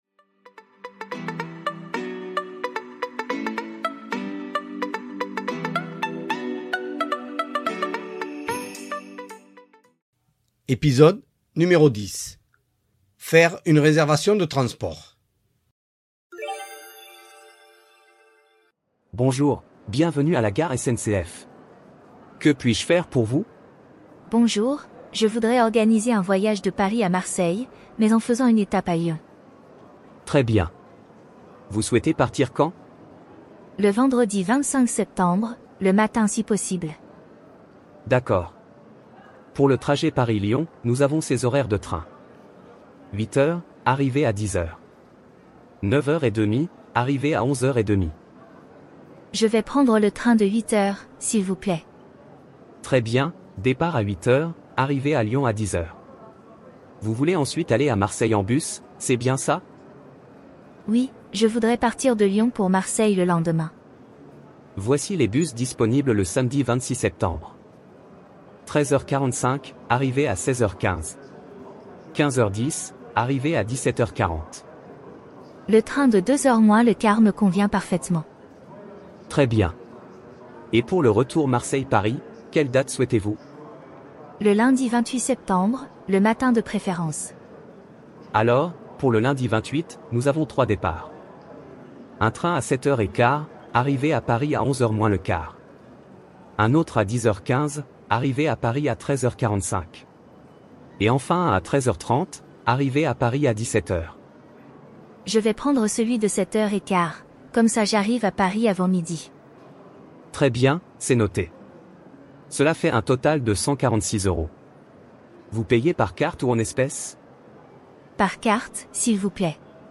Voici un petit dialogue pour les débutants. Avec cet épisode, vous verrez quelques expressions pour faire un réservation de train ou de bus.